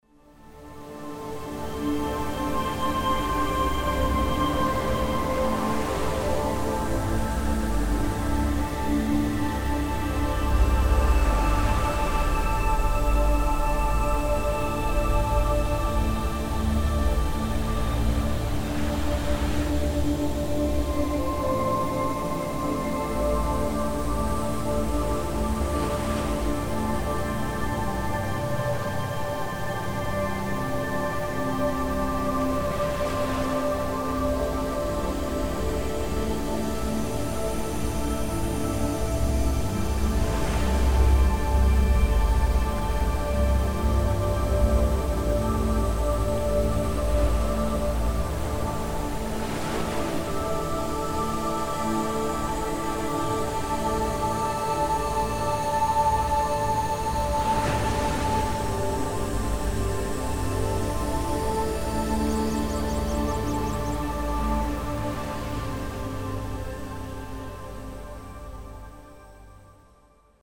האזנה לדוגמה אחת עם טונים בינוראלים:
זריקת מרץ לבוקר עם טון בינוראלי